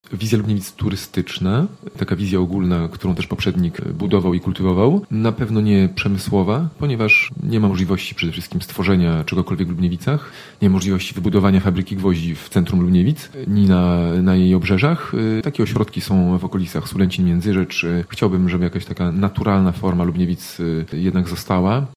Zdaniem porannego gościa Radia Zachód w Lubniewicach nigdy nie powstaną fabryki czy obiekty przemysłowe: